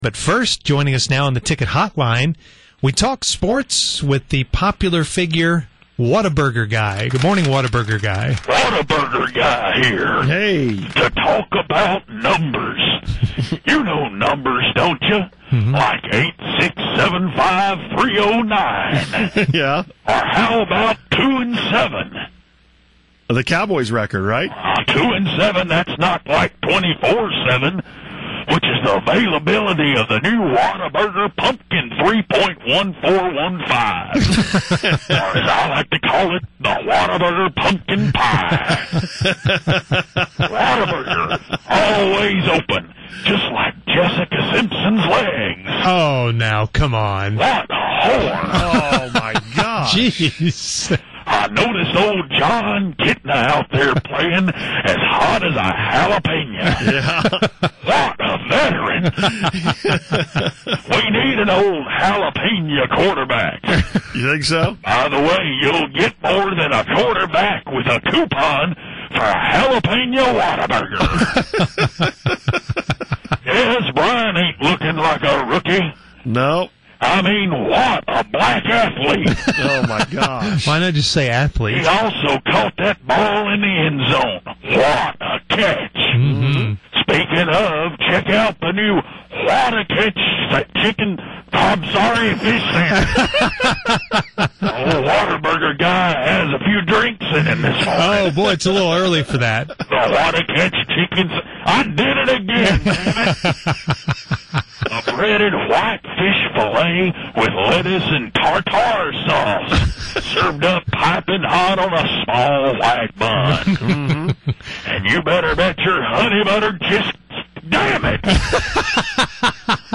The very fake Whataburger Man called in to the Musers show this morning to share his Cowboy sports opinions.